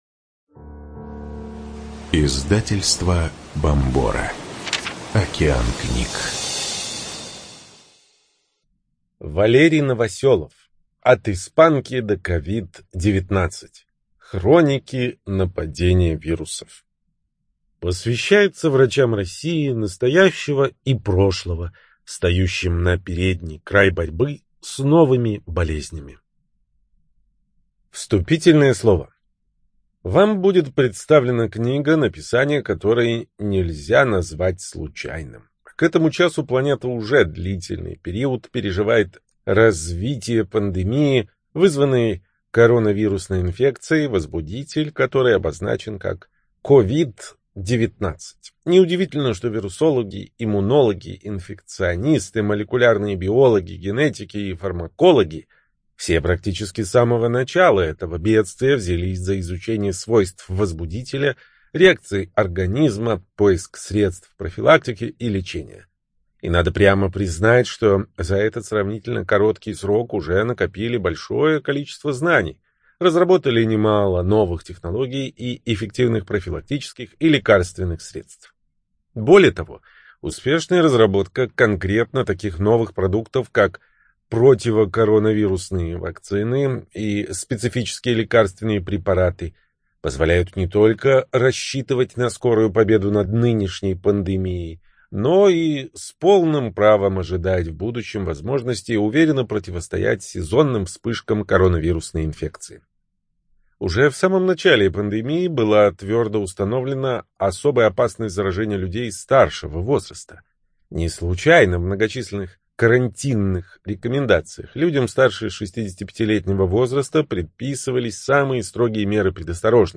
Студия звукозаписиБомбора